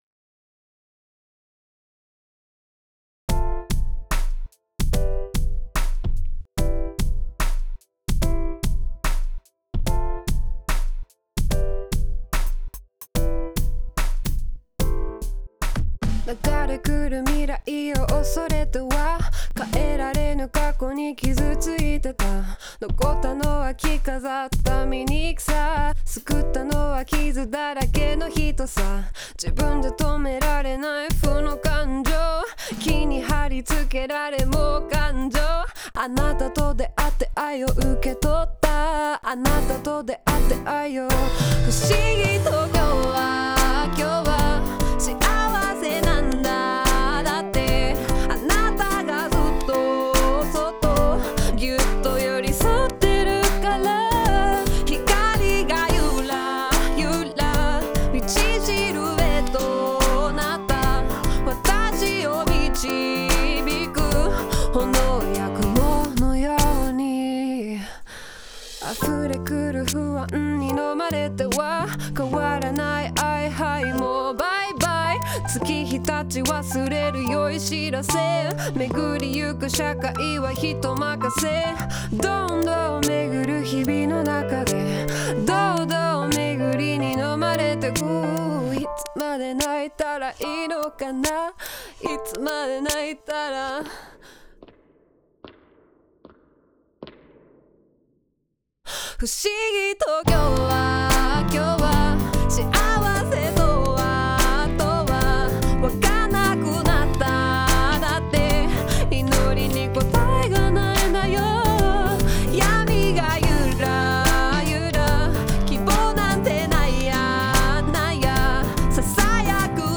オリジナルKey：「C